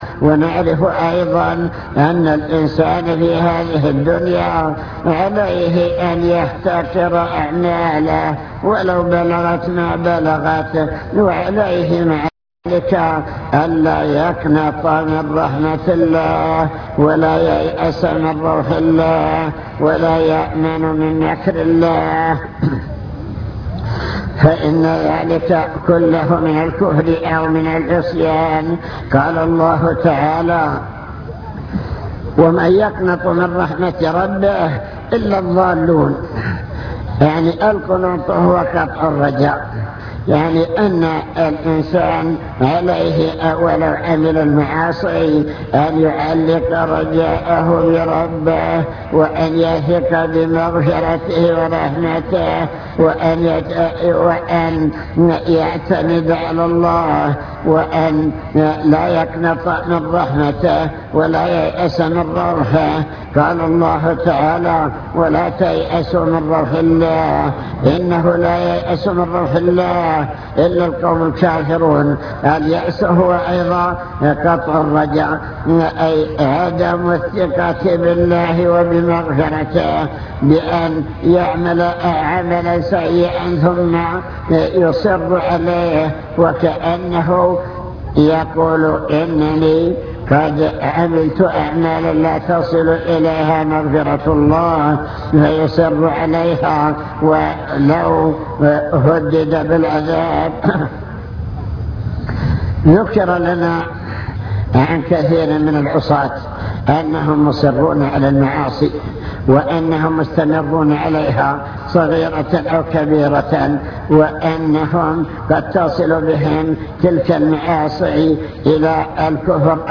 المكتبة الصوتية  تسجيلات - محاضرات ودروس  محاضرة في بني زيدان